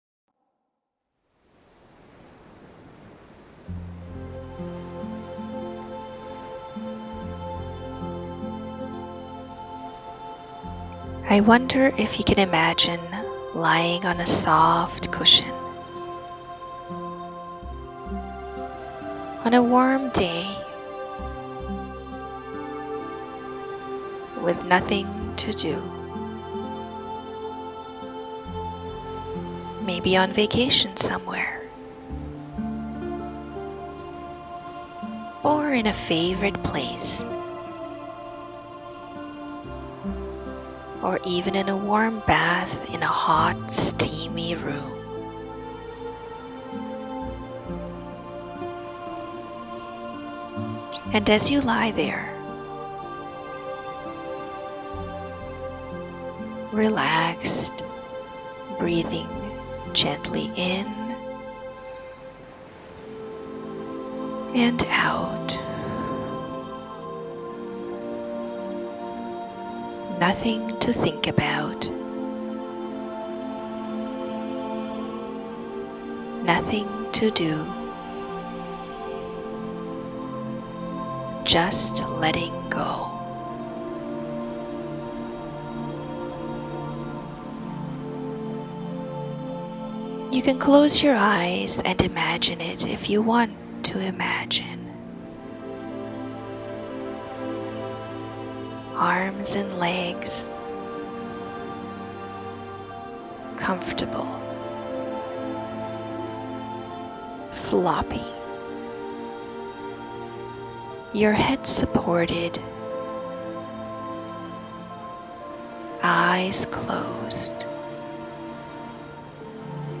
Please enjoy this audio relaxation mp3 download.
Island-of-Calm-Hypnosis.mp3